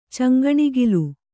♪ caŋgaṇigilu